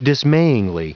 Prononciation du mot dismayingly en anglais (fichier audio)
Prononciation du mot : dismayingly